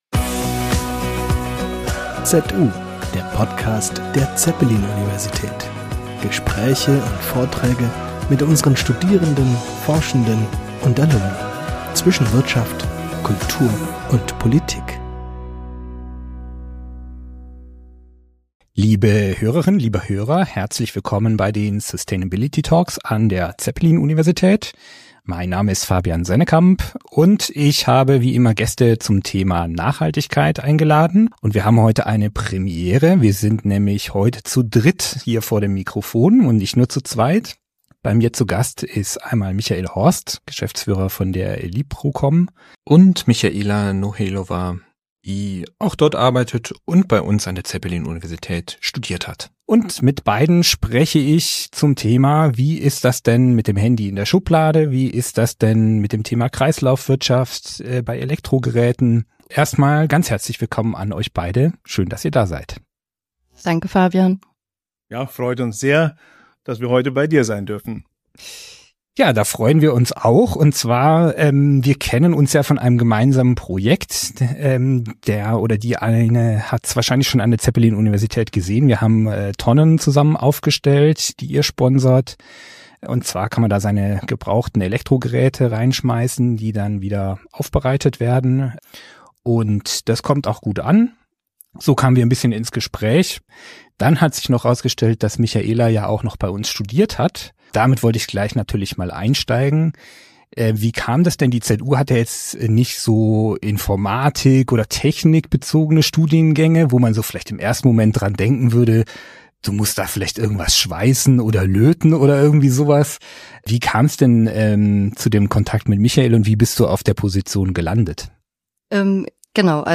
Sustainability Talk